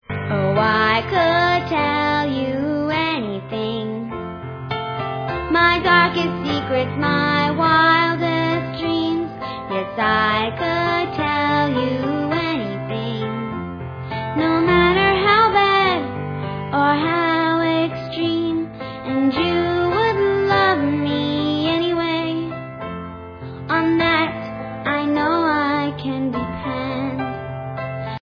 From bouncy and boisterous to warm and furry